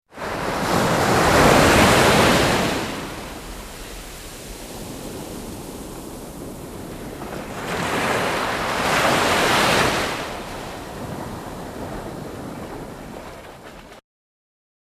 自然界
波（234KB）